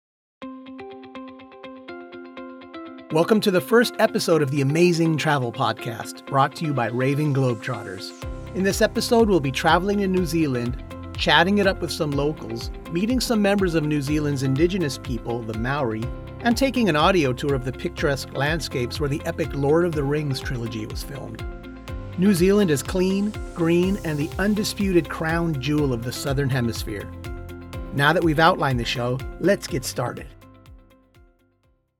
Podcast - Real person, authentic, educational
My voice quality is clear with a slight amount of "grit" that lends to its uniqueness.